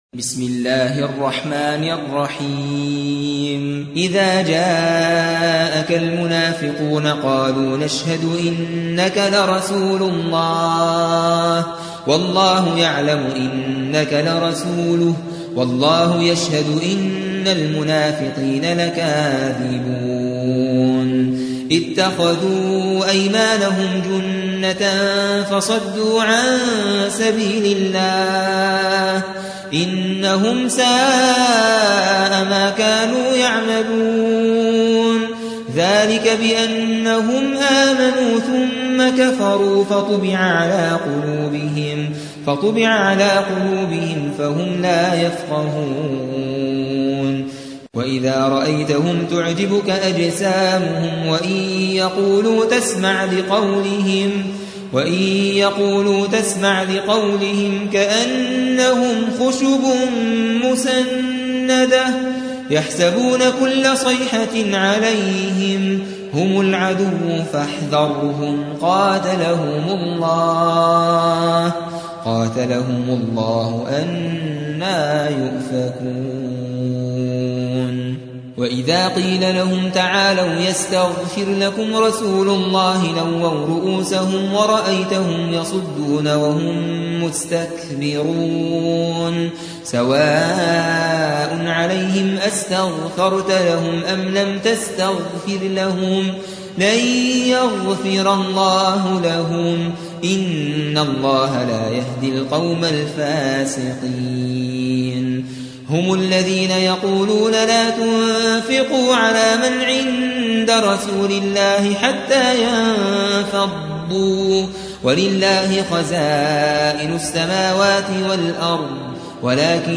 63. سورة المنافقون / القارئ